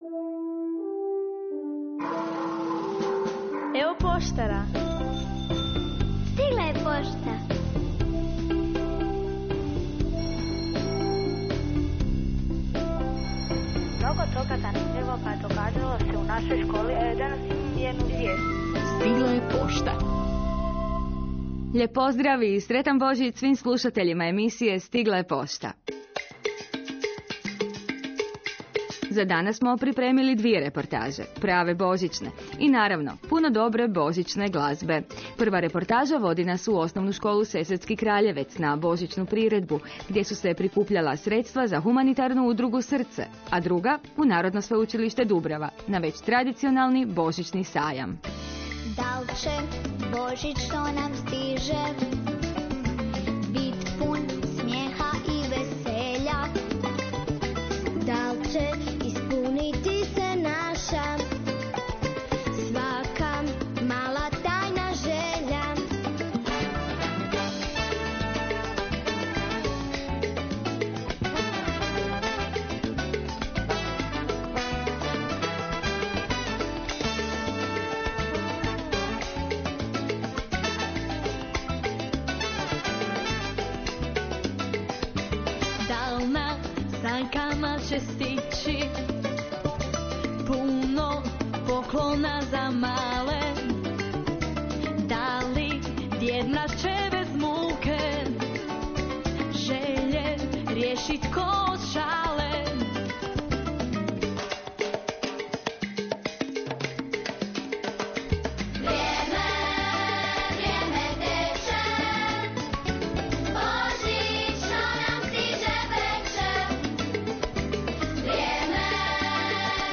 Pozivamo vas da poslušate snimku bo�i�ne priredbe naših u�enika koja je odr�ana 16. prosinca u kino dvorani S. Kraljevec.